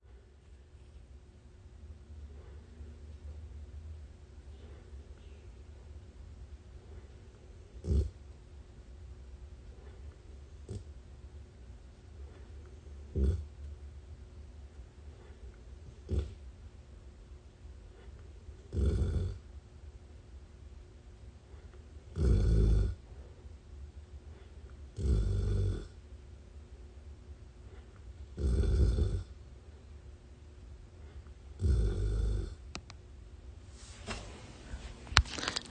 A recording of Baloo, my 100 lb Labrador snoring, they are mighty, respectable snores. Bitch is sawing some logs I'ma tell you right now.